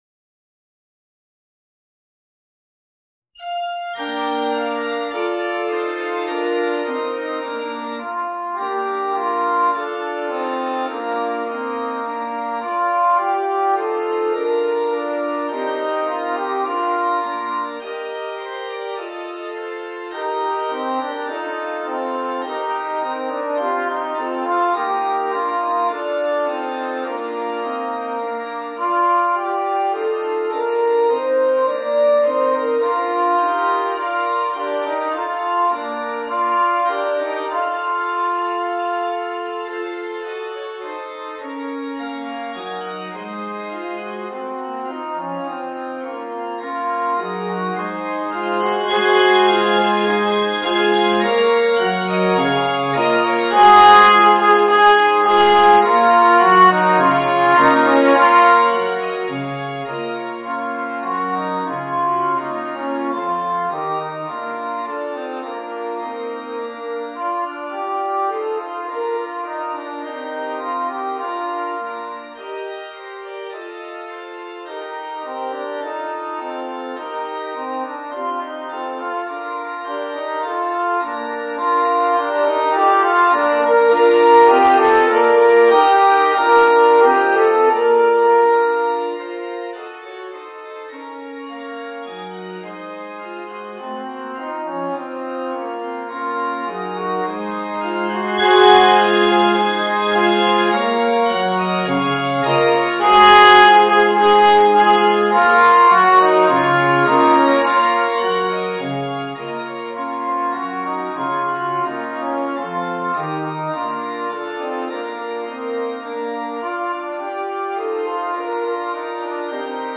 Voicing: Alto Trombone and Organ